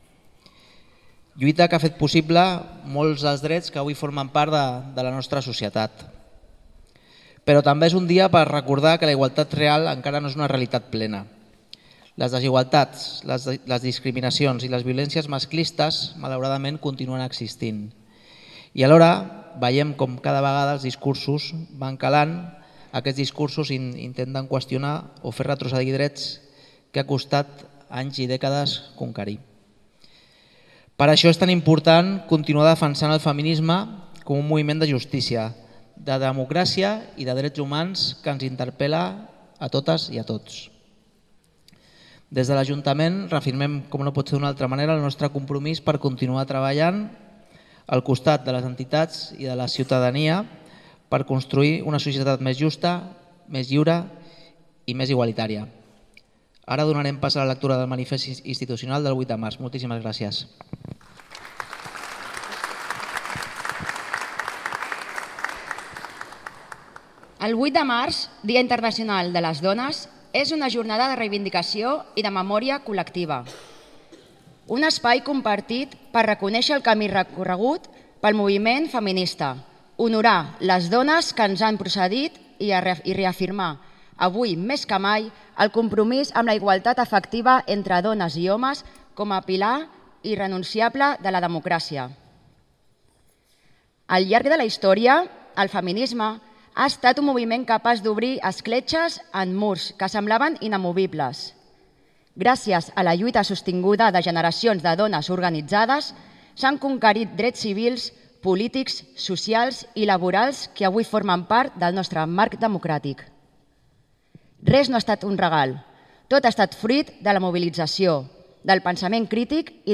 En el marc de l’exposició ‘Mirades emergents’ que podeu veure aquests dies al mercat vell, i que presenta a través de magnífiques fotografies i articles situacions de desigualtats i vulneracions de drets humans arreu del món, aquest migdia ha tingut lloc l’acte de commemoració del dia internacional de les dones. L’acte ha començat amb les paraules del regidor de drets socials, ciutadania i igualtat, Xavier Ripoll i ha continuat amb la lectura de la declaració institucional de la diada.
Finalment, l’alcaldessa Aurora Carbonell ha tancat l’acte, tot recordant la importància històrica i la vigència que continua tenint la commemoració del 50è aniversari de les primeres jornades catalanes de la dona, que varen tenir lloc al paranimf de la universitat de Barcelona.